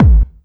Hard Bd.wav